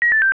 calldrop.wav